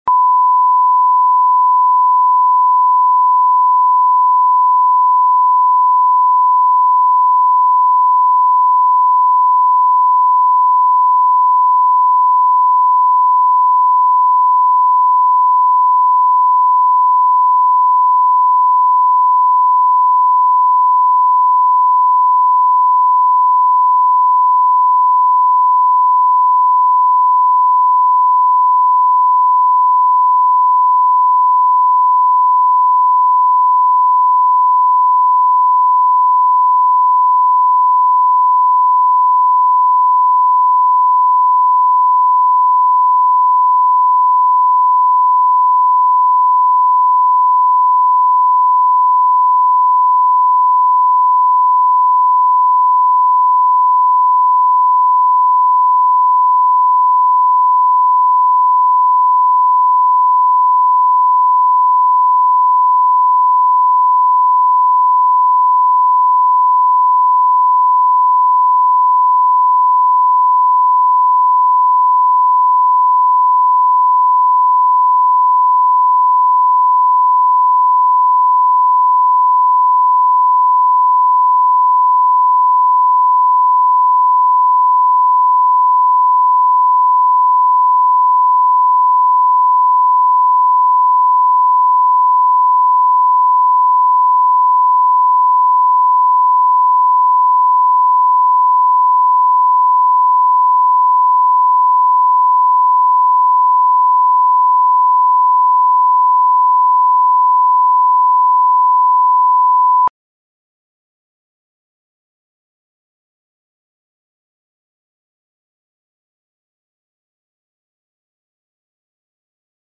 Аудиокнига Золотой стежок